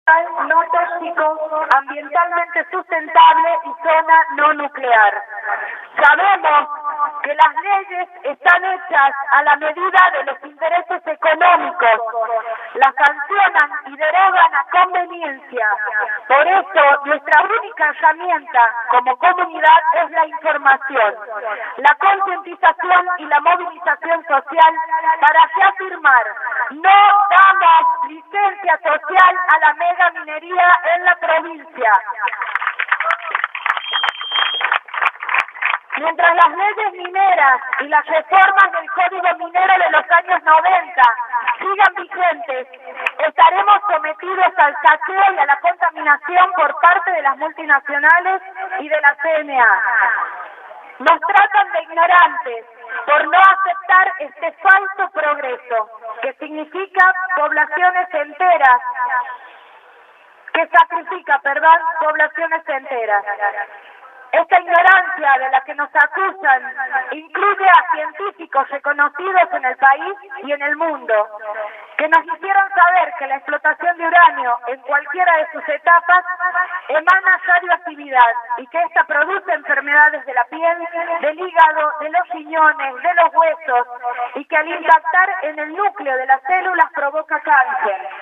Lectura del documento de la Asamblea Capital
lectura-del-documento-de-asamblea-capital1.mp3